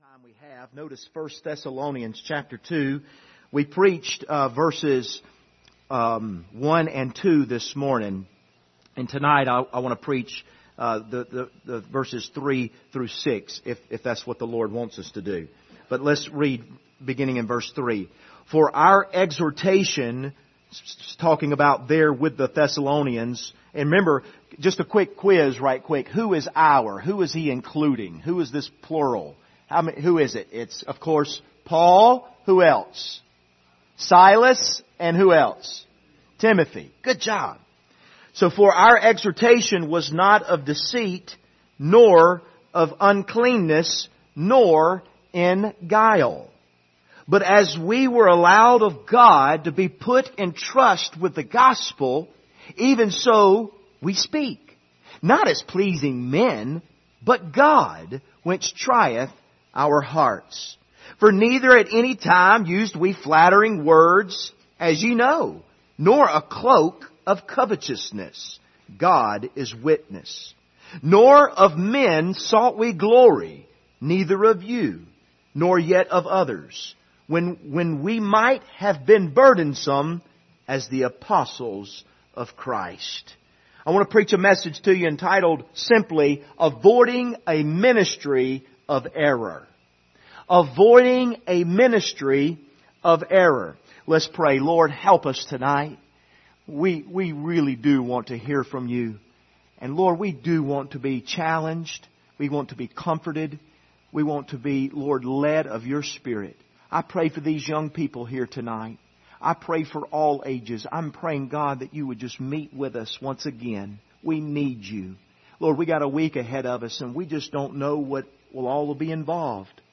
1 Thessalonians Passage: 1 Thessalonians 2:3-6 Service Type: Sunday Evening « Praying for Our Children What Will You Be Remembered For?